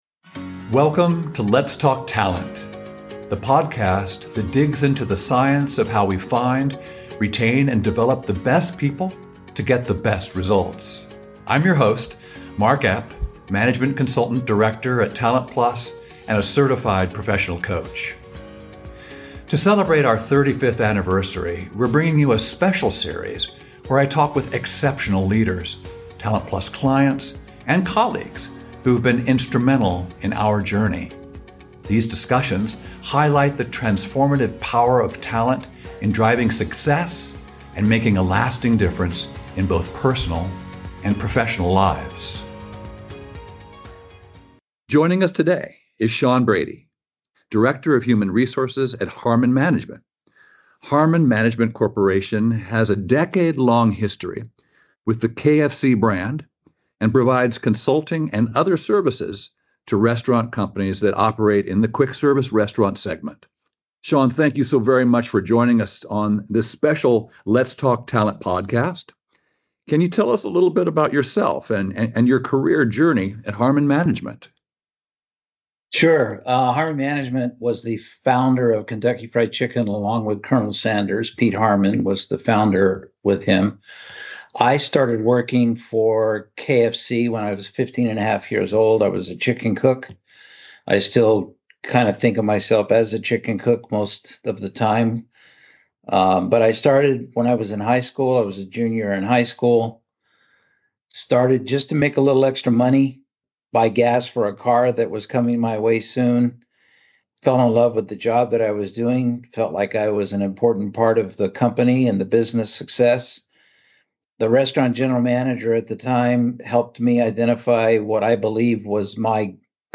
Join us as we interview seasoned talent experts who share stories and give insight into how your organization can reach its full potential — through the power of natural talent.